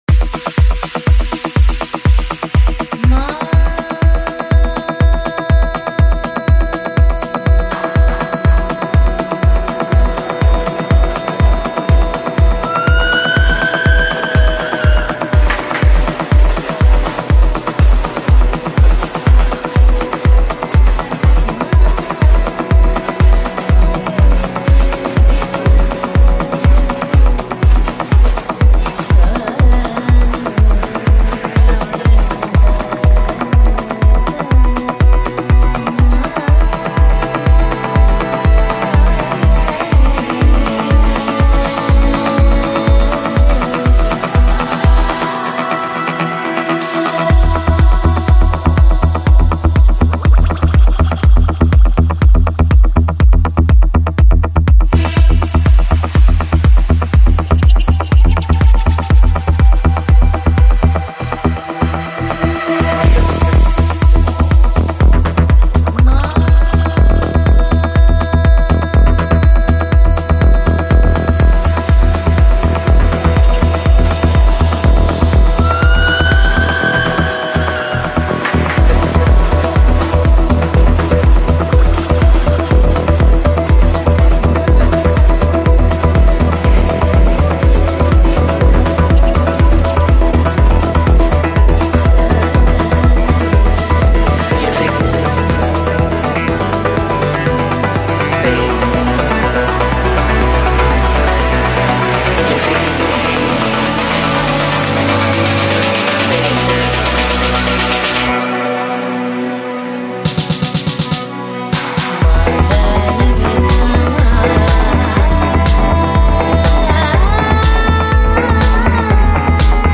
Progressive House Mix